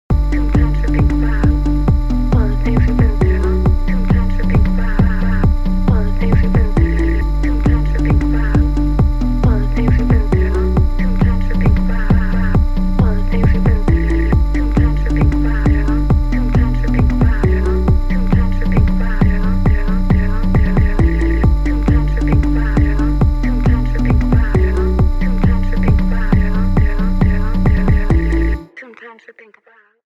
Without Instant Sidechain